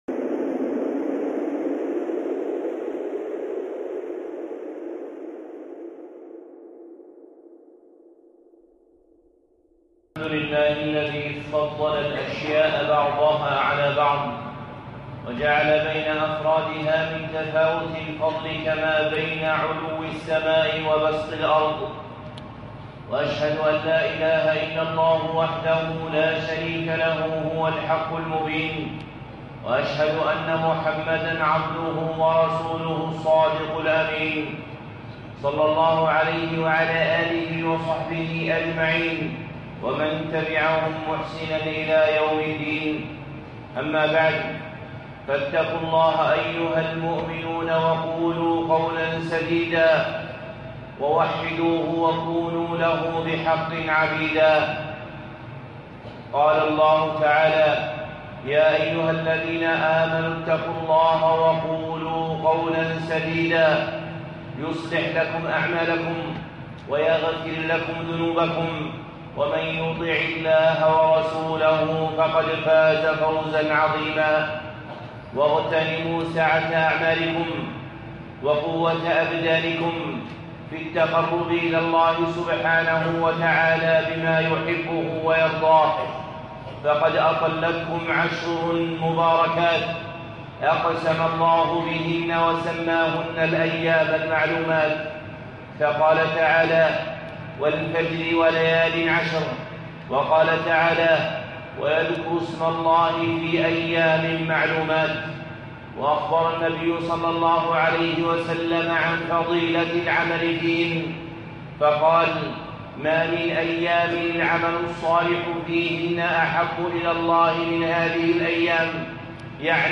خطبة (شكر العشر